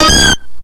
Cri de Toudoudou dans Pokémon X et Y.